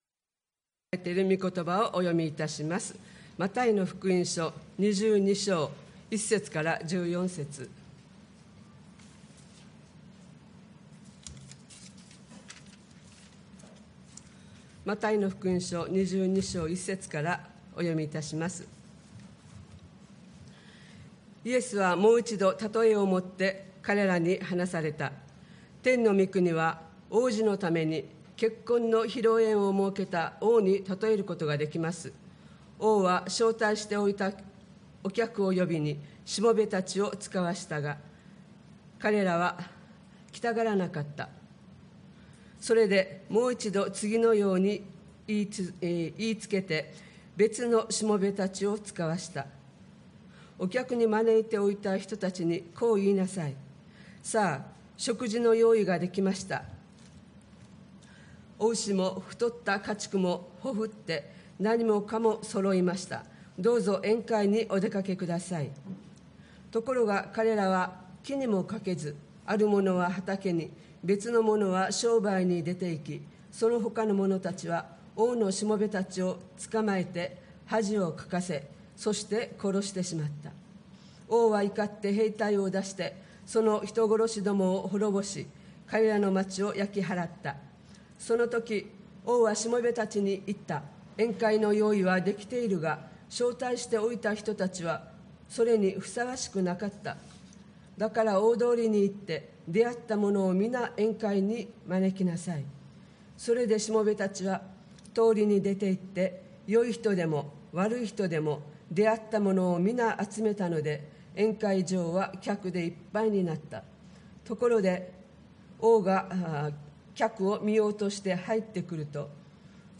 礼拝メッセージ(説教)